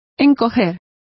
Complete with pronunciation of the translation of shrank.